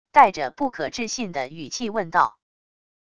带着不可置信的语气问道wav音频